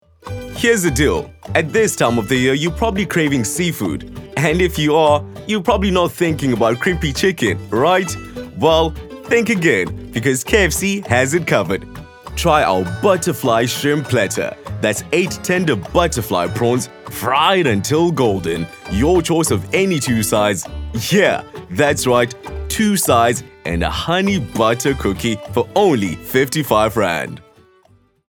cultured, elegant, polished, refined
My demo reels